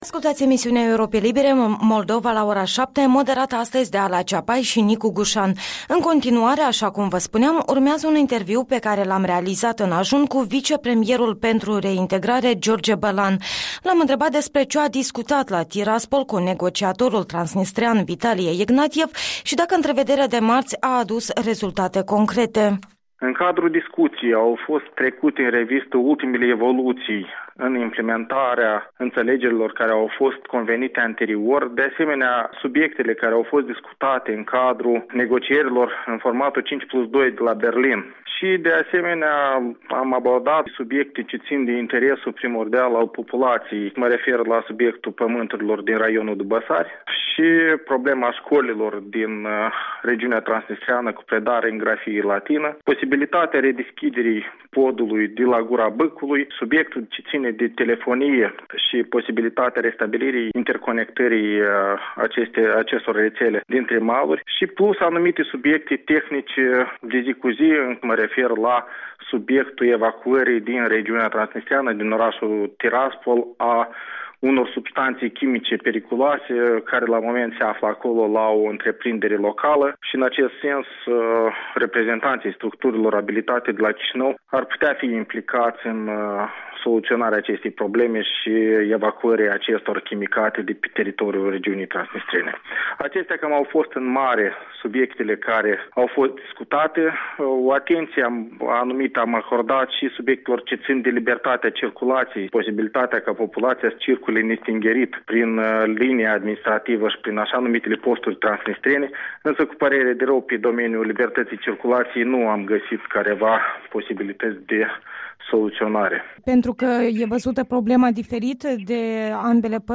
Interviul dimineții cu vicepremierul pentru reintegrare.